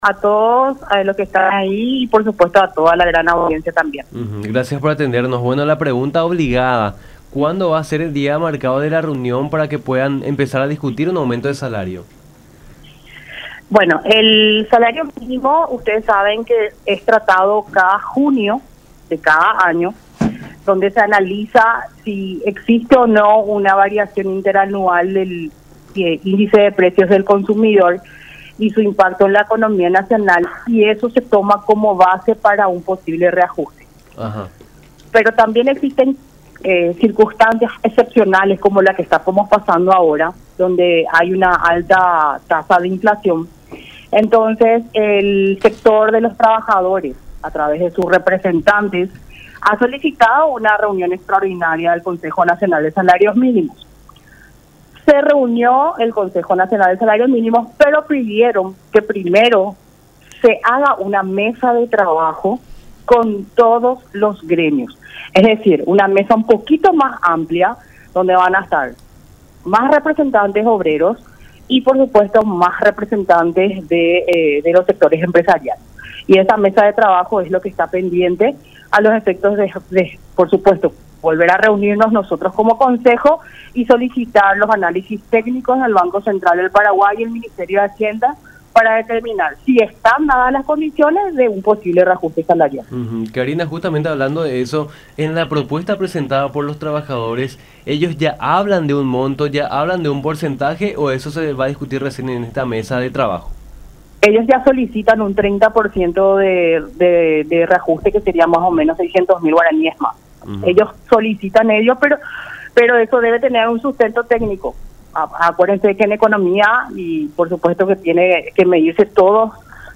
Hubo reuniones, pero se tiene que hacer una mesa de trabajo con todos los gremios, es decir, una mesa mucho más amplia para solicitar los informes técnicos del BCP y el Ministerio de Hacienda”, explicó Karina Gómez, directora de Empleo de del Ministerio del Trabajo, en diálogo con Nuestra Mañana por La Unión.